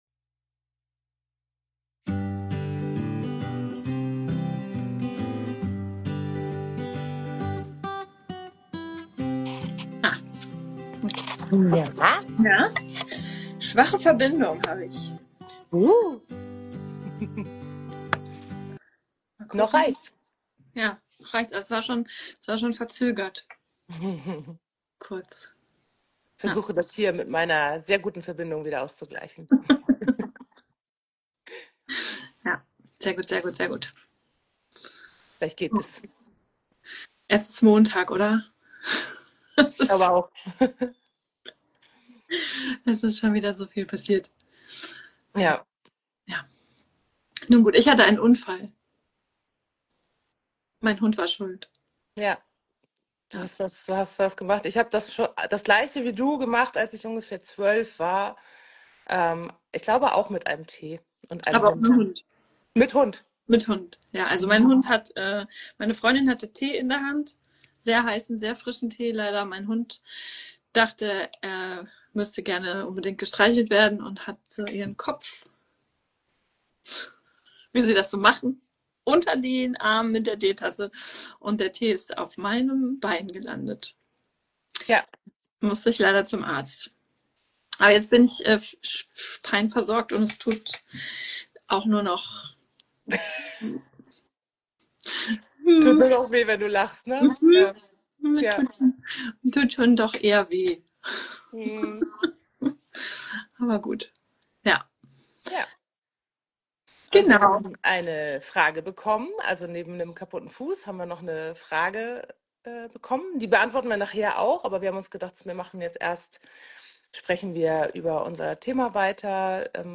In unserer Instagram Live Reihe beschäftigen wir uns im Moment intensiv mit den Besonderheiten von Hibbelhunden in Deinem Training. In der vierten Folge sprechen wir über das große Thema Beschäftigung für Hibbelhunde und welche Besonderheiten da zu beachten sind.